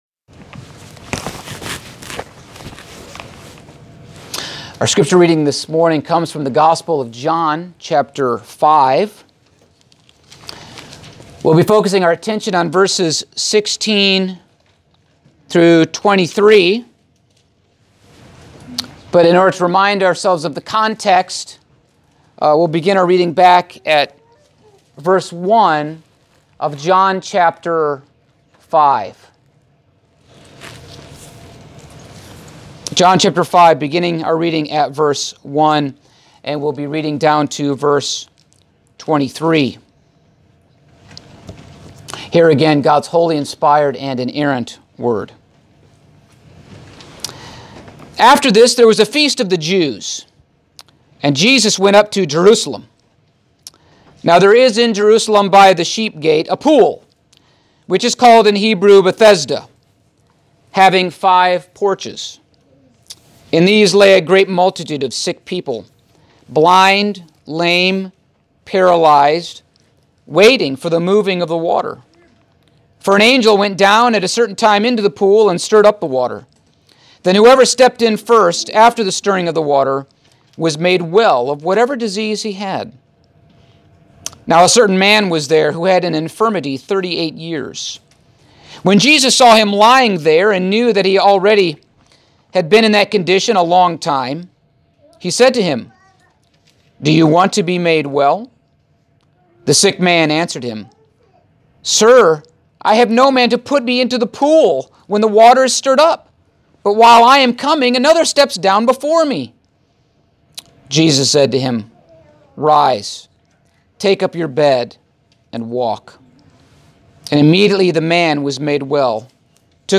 Passage: John 5:16-23 Service Type: Sunday Morning